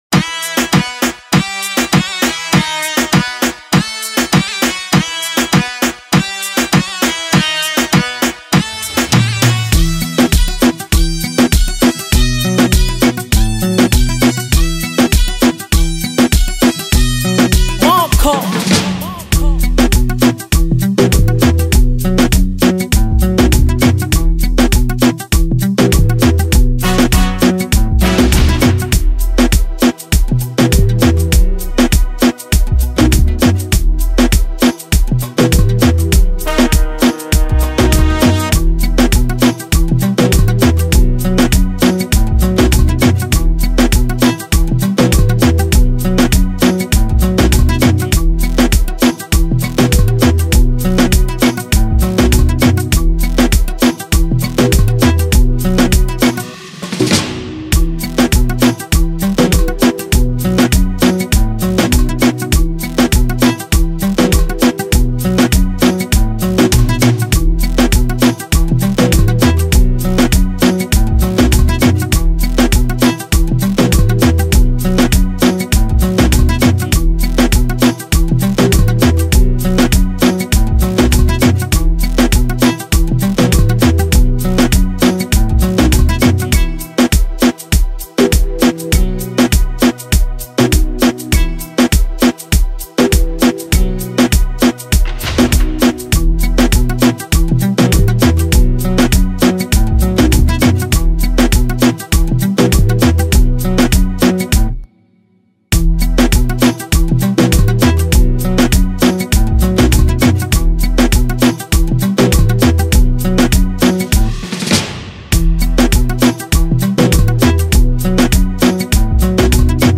Category:   Bongo Flava ,   Audio